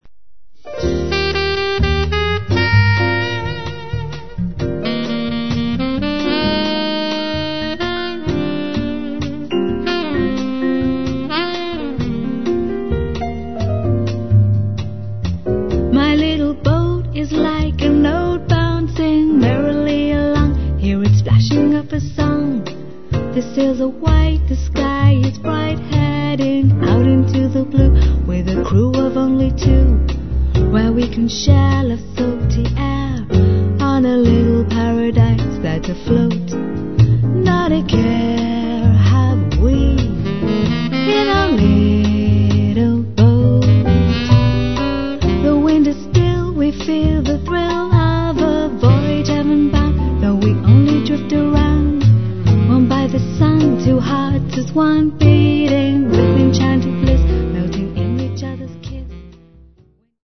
vocals
piano
double bass
drums
saxophone